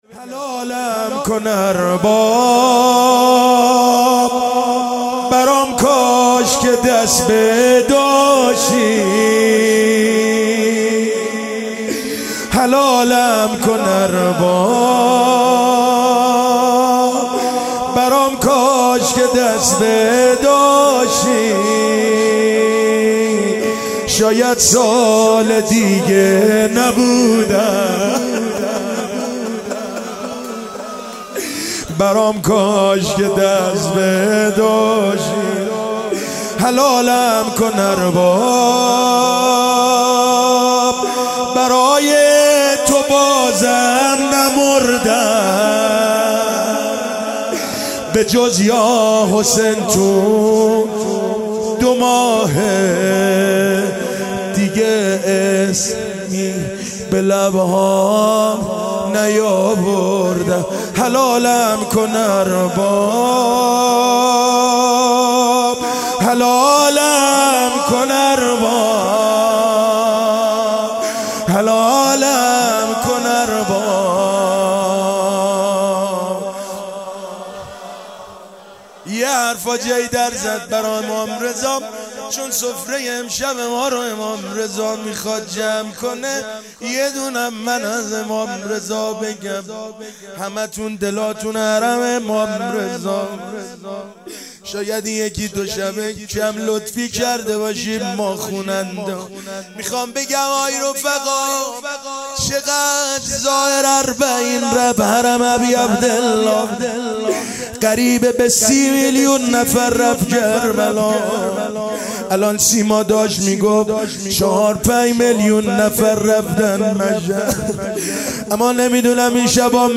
مناسبت : شهادت امام رضا علیه‌السلام
قالب : شعر خوانی